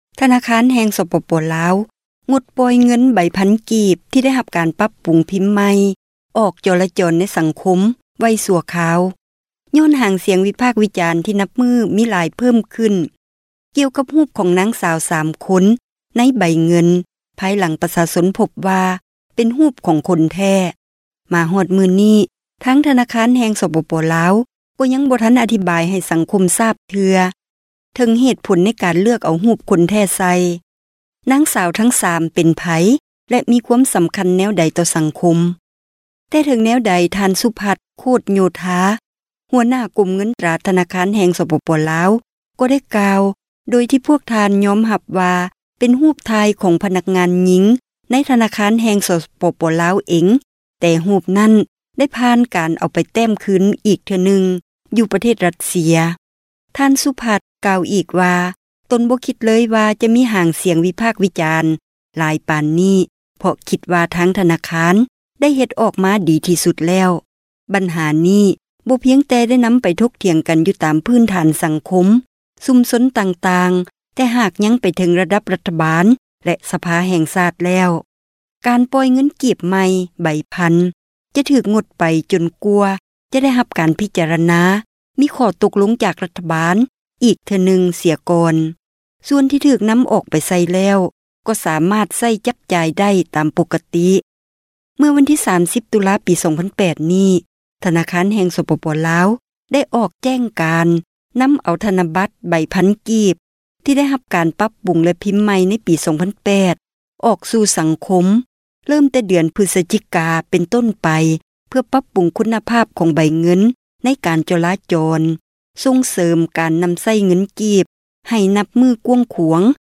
ຣາຍງານ